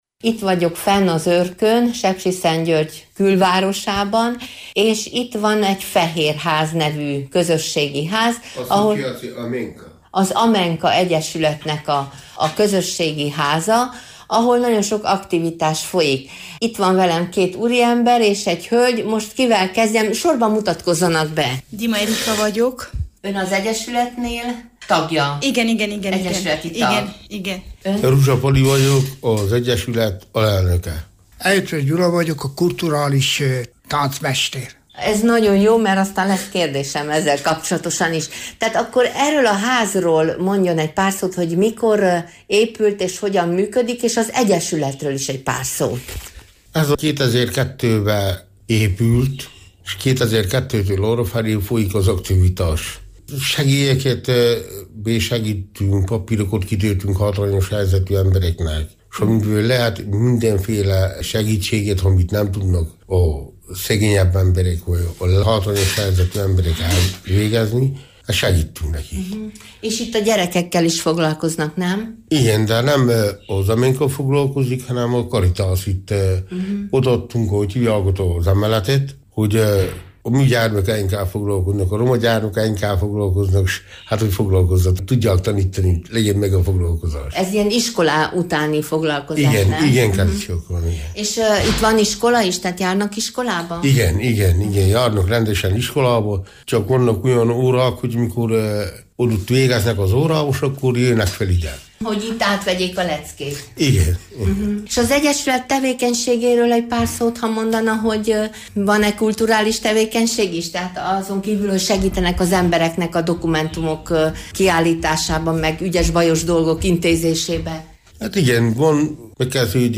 Kedves hallgatók, Őrkői témák többször szerepeltek már a műsorban, de először volt alkalmunk kiszállni a Sepsiszentgyörgy külvárosához tartozó roma negyedbe.